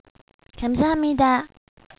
Thank you - [gamsahamnida]